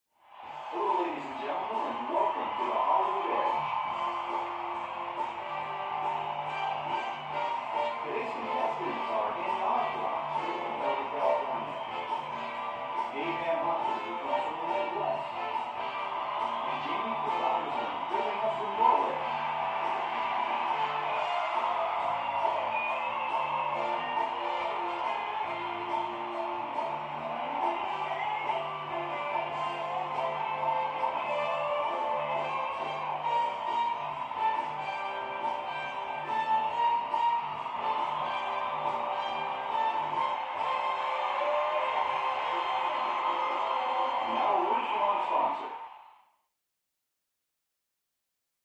Television; Game Show Opening With Music, Announcer And Crowd. From Next Room.